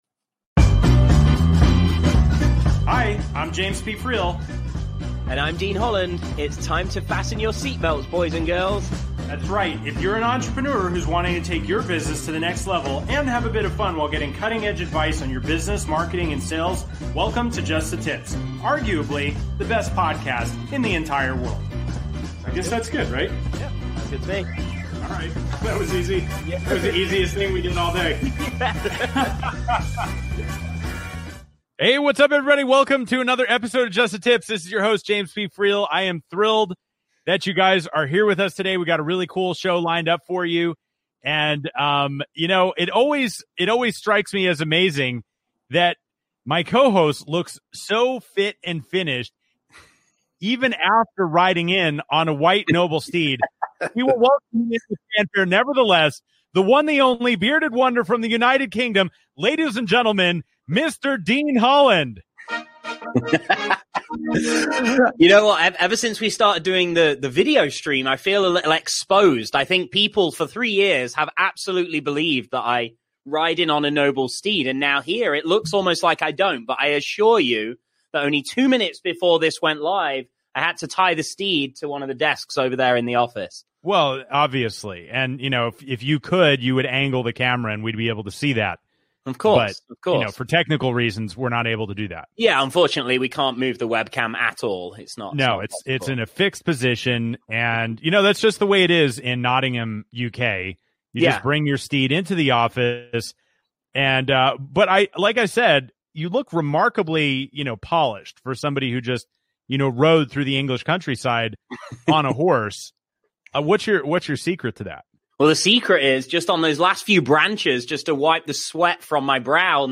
Just The Tips is a business talk show for entrepreneurs and business owners who are tired of listening to the same old stodgy content. Interviewing (and sometimes roasting) top entrepreneurs from around the world, each episode is fun, witty and informative.